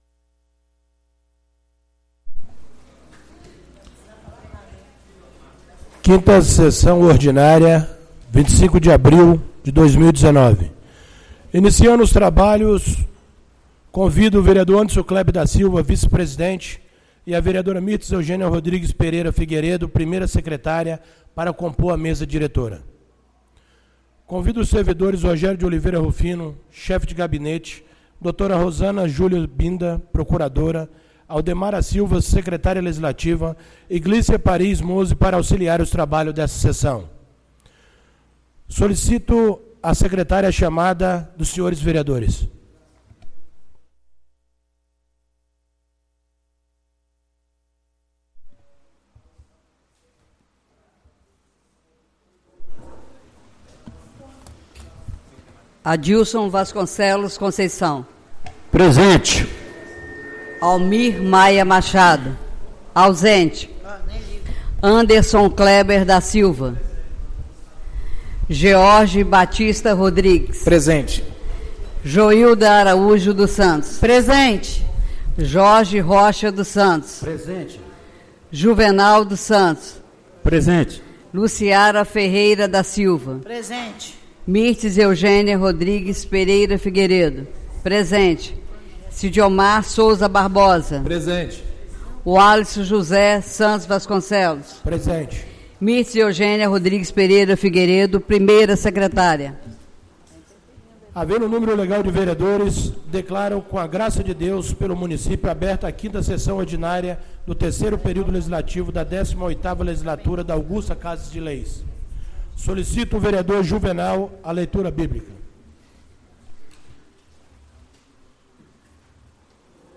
5ª Sessão Ordinária do dia 25 de abril de 2019